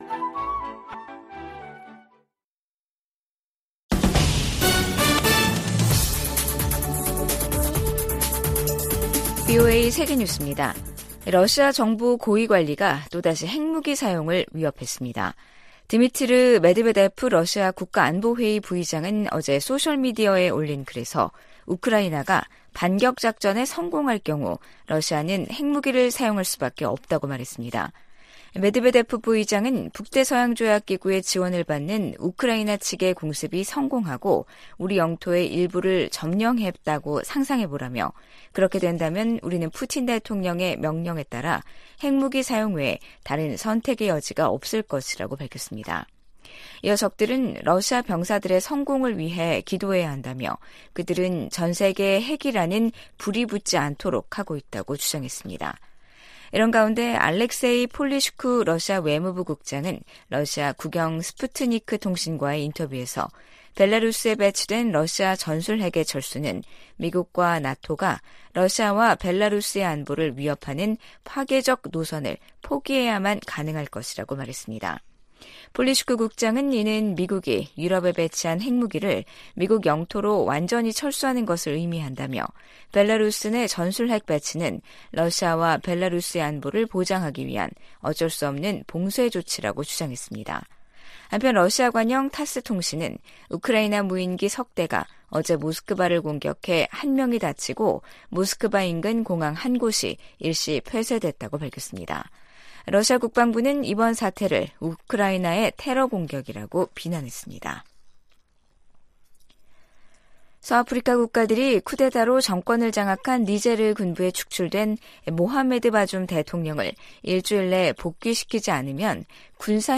VOA 한국어 간판 뉴스 프로그램 '뉴스 투데이', 2023년 7월 31일 2부 방송입니다. 백악관이 미한일 3국 정상회담 개최를 공식 발표하며 북한 위협 대응 등 협력 확대 방안을 논의할 것이라고 밝혔습니다. 미 국무부는 줄리 터너 북한인권특사 지명자에 대한 상원 인준을 환영했습니다. 미 상원이 2024회계연도 국방수권법안을 통과시켰습니다.